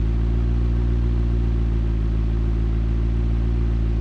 ttv8_01_idle.wav